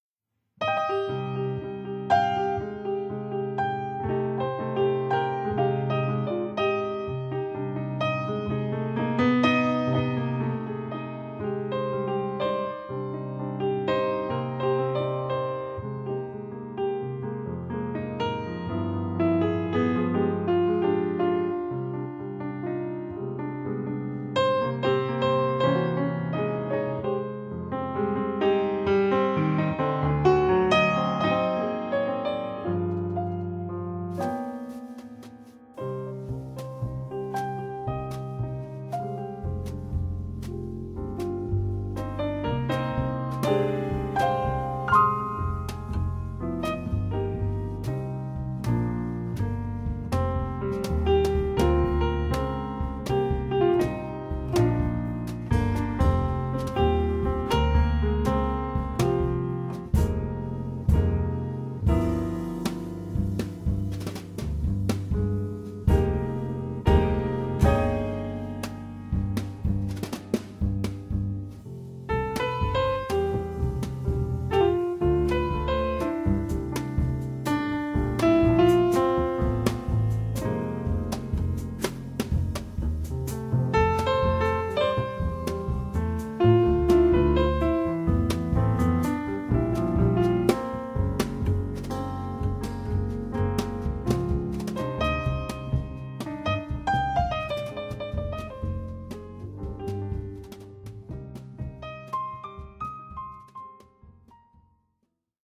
modern, avant-garde Latin jazz
double bass